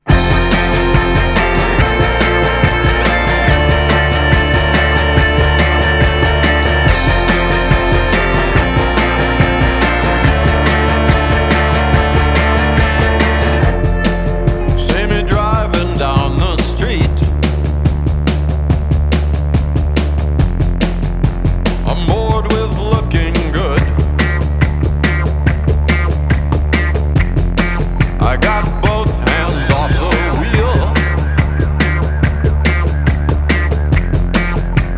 Жанр: Рок / Альтернатива / Электроника